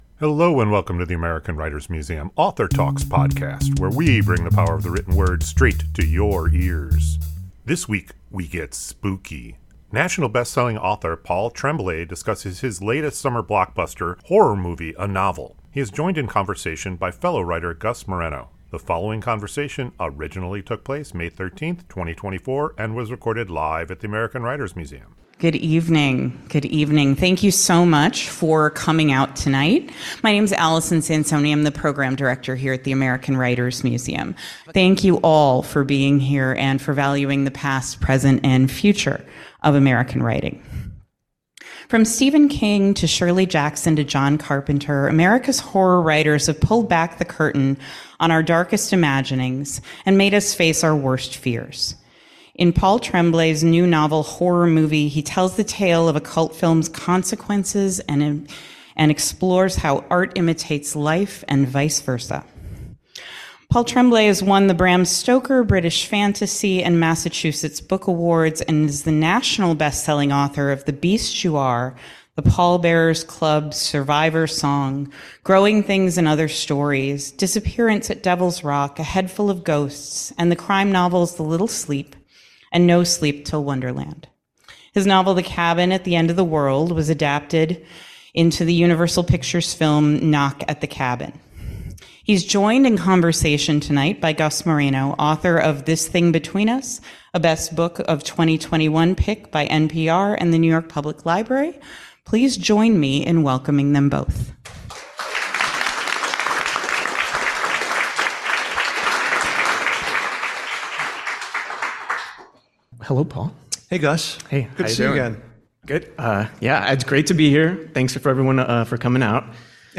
AWM Author Talks